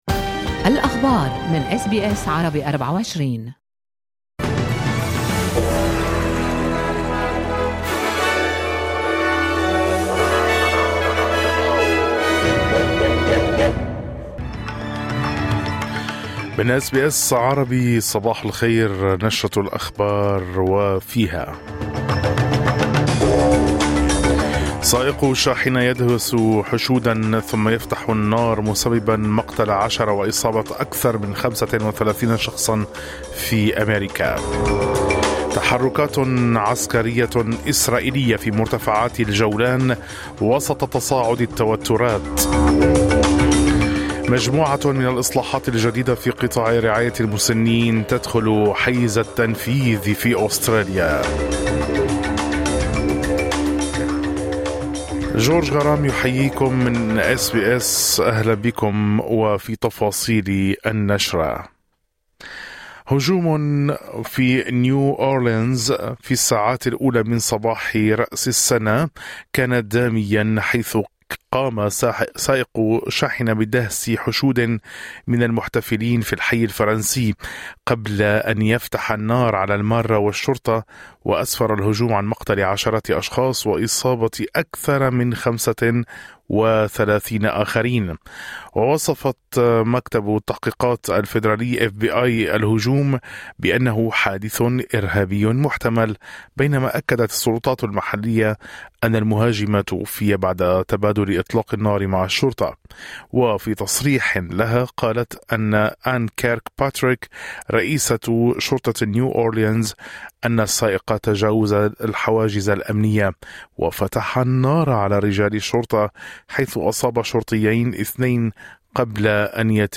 نشرة أخبار الصباح 02/01/2025
نشرة الأخبار